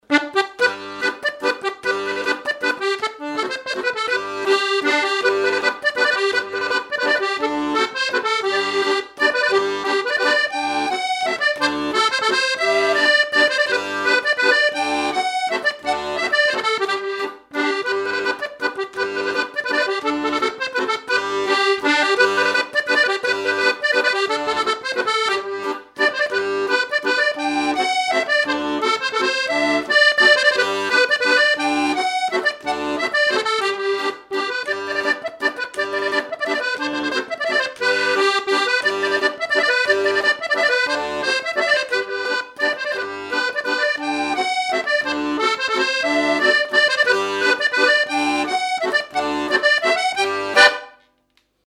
danse : mazurka
Pièce musicale inédite